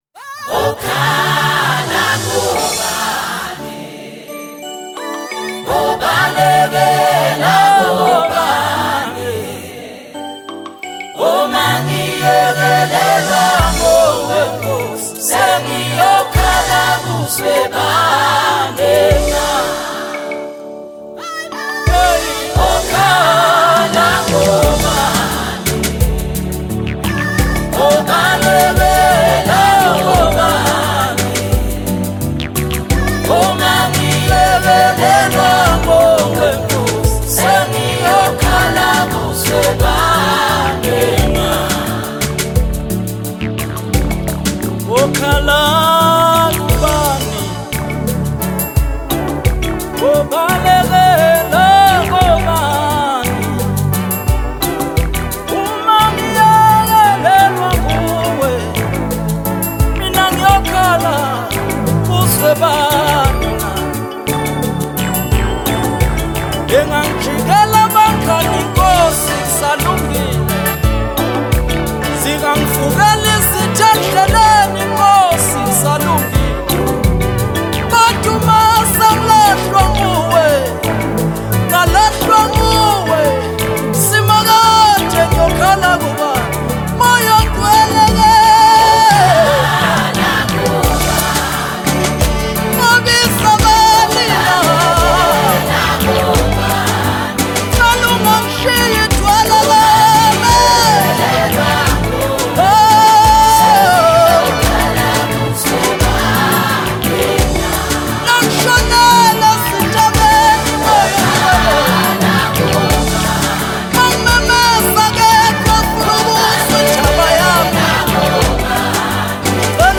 praise/worship song
Genre : SA Gospel